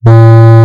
B E E P
beep_uMKFlfT.mp3